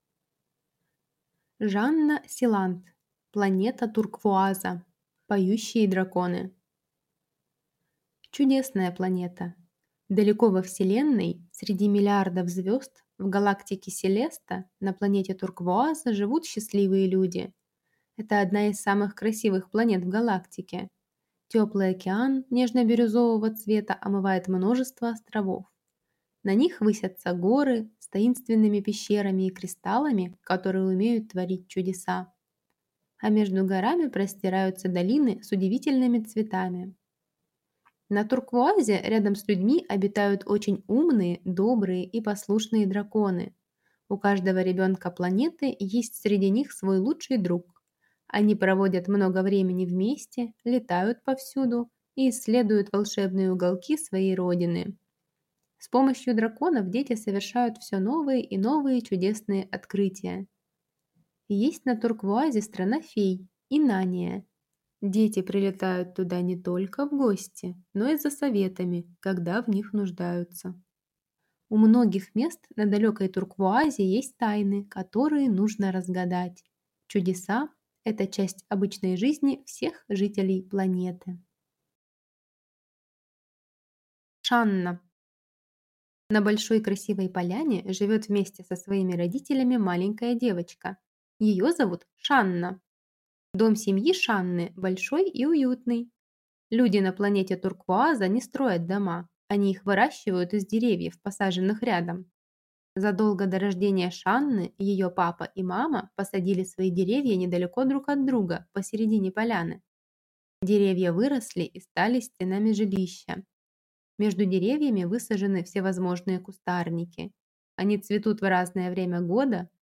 Аудиокнига Планета Турквуаза. Поющие драконы | Библиотека аудиокниг